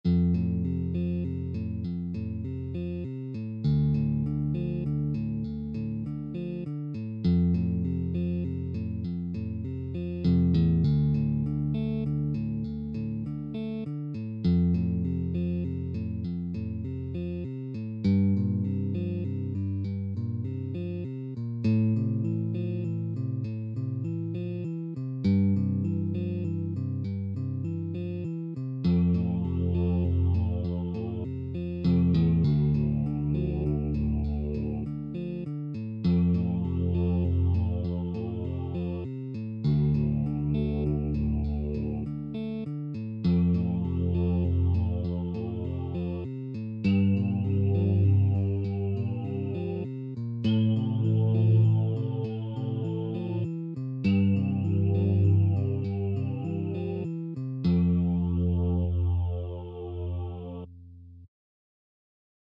Вот карочи типа грусная музыка. Придумал на гитаре